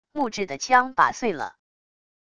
木制的枪把碎了wav音频